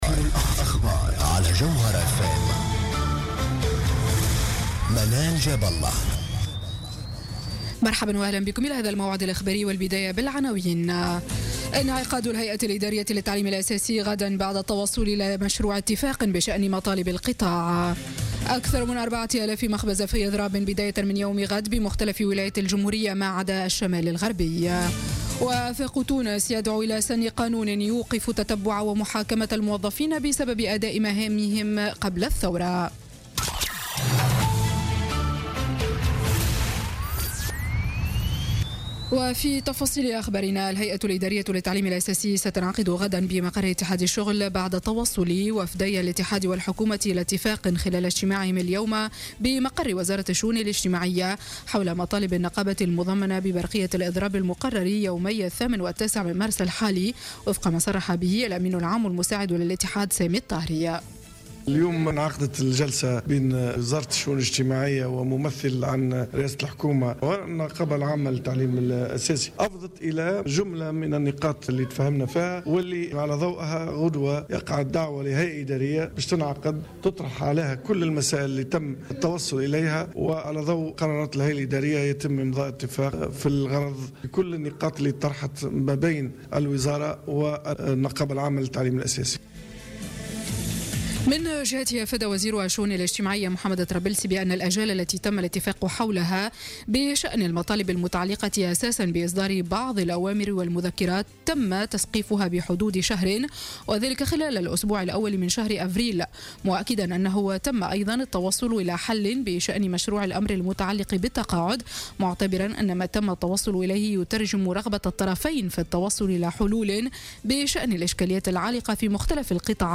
نشرة أخبار السابعة مساء ليوم الأحد 5 مارس 2017